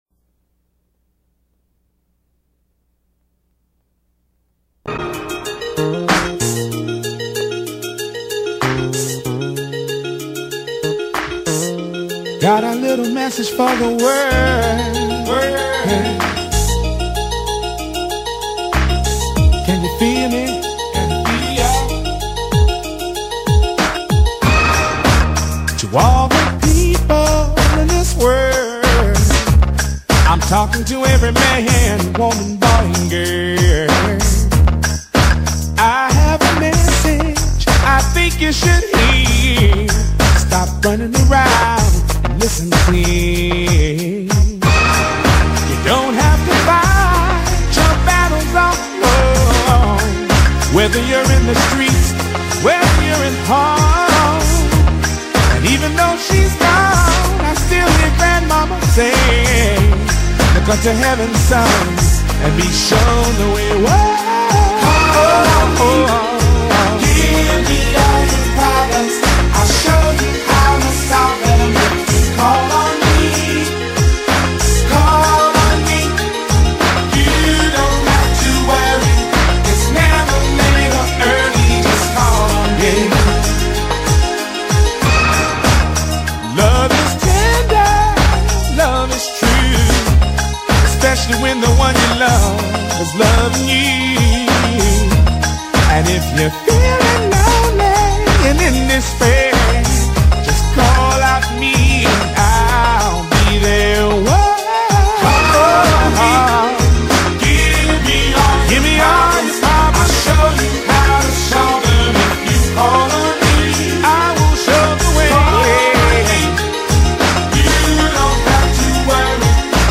VOCALIST
/PIANIST/SONGWRITER